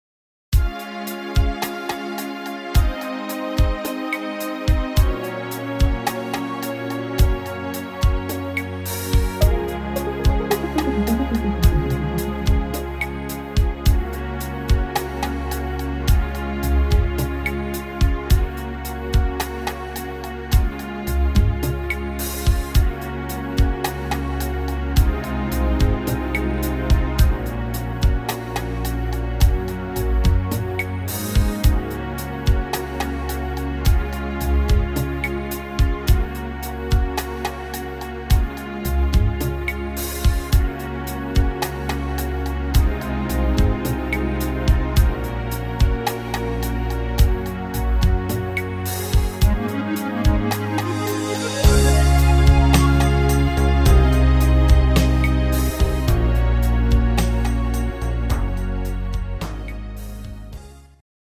Rhythmus  Rhumba
Art  Englisch, Oldies